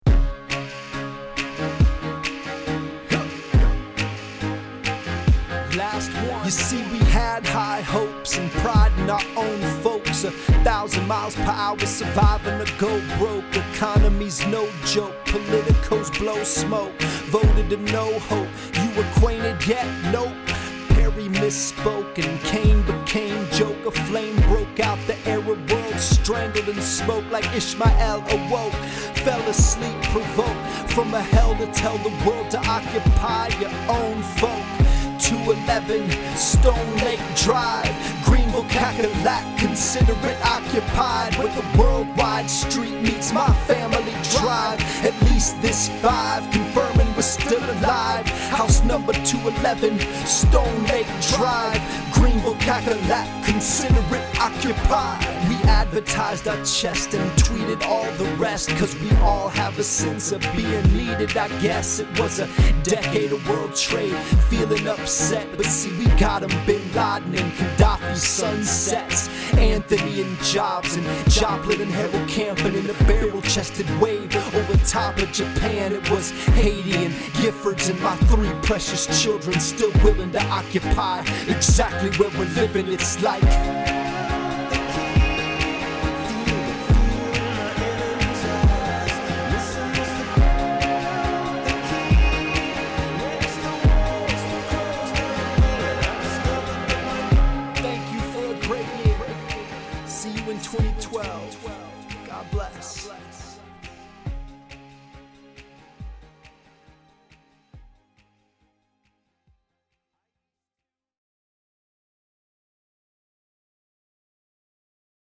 I promise to work even harder at rap news in 2012.